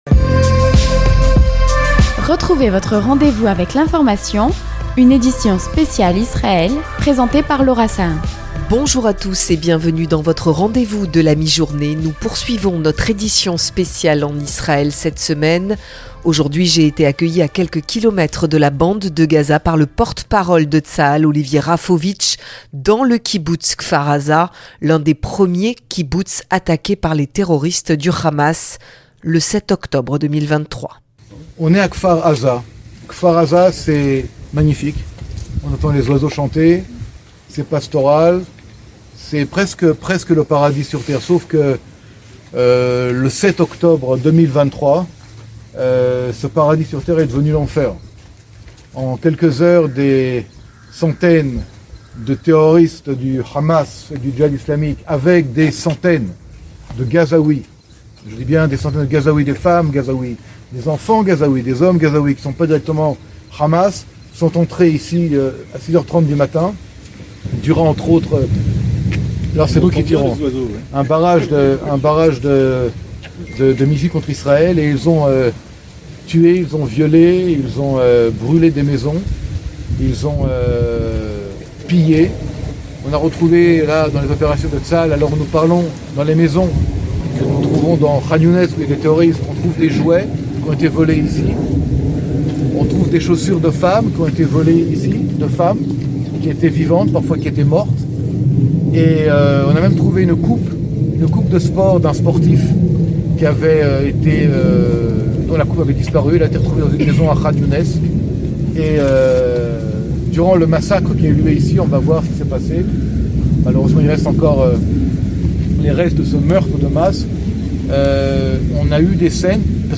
Retrouvez le reportage en direct du kibboutz de Kfar Azar avec Olivier Rafowicz, porte-parole de Tsahal.